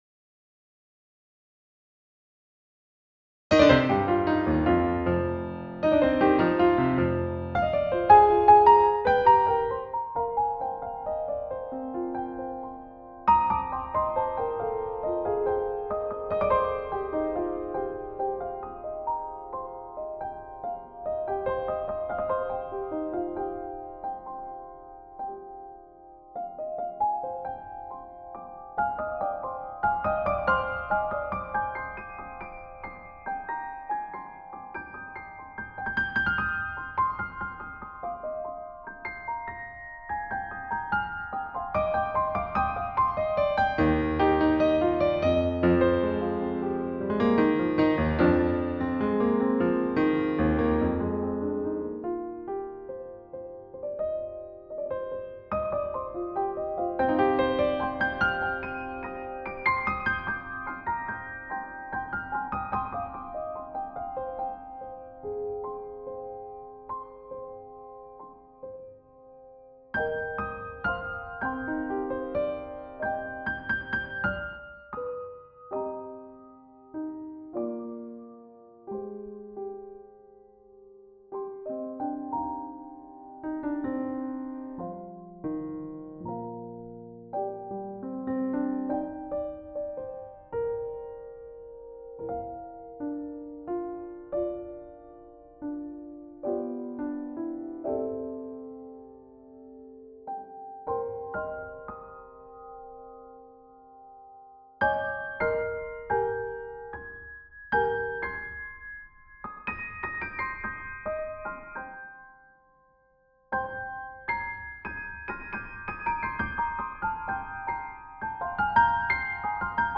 Music: Piano/ Logic